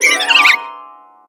Grito de Roserade.ogg
Grito_de_Roserade.ogg